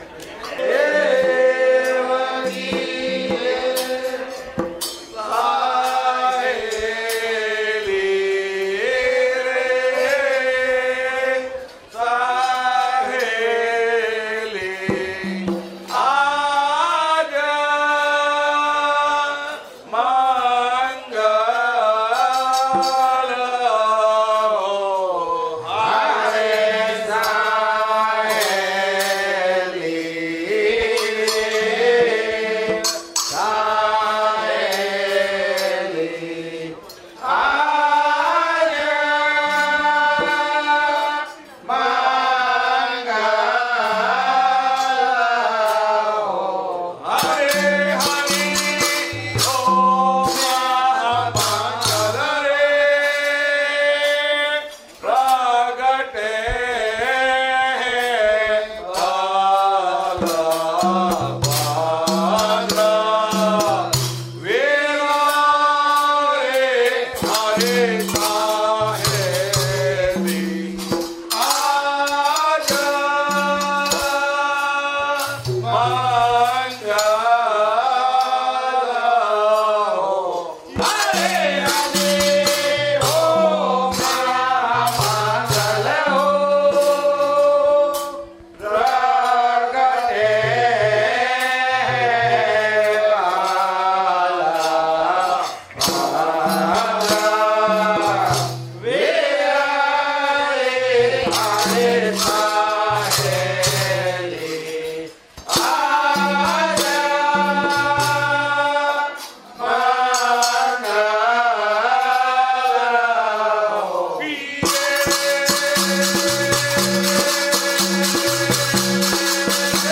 રાગ - સામેરી રાસ